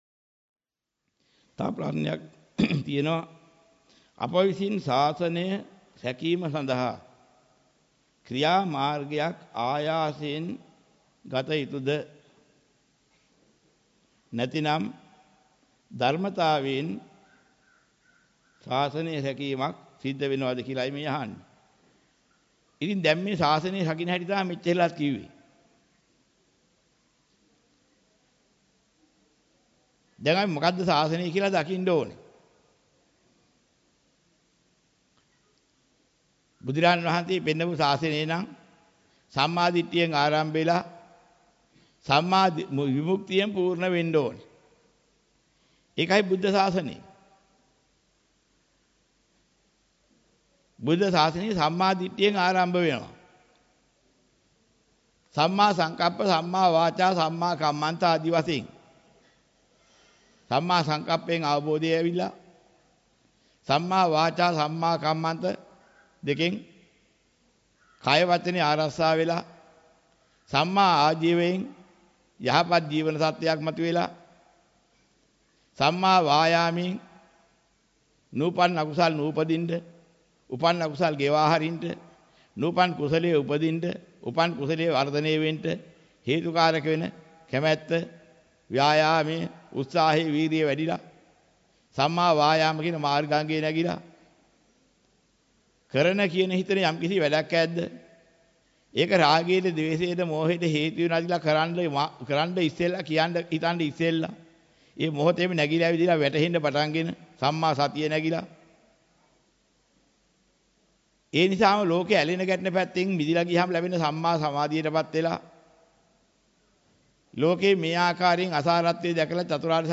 වෙනත් බ්‍රව්සරයක් භාවිතා කරන්නැයි යෝජනා කර සිටිමු 06:34 10 fast_rewind 10 fast_forward share බෙදාගන්න මෙම දේශනය පසුව සවන් දීමට අවැසි නම් මෙතැනින් බාගත කරන්න  (4 MB)